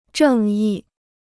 正义 (zhèng yì): justice; righteousness.
正义.mp3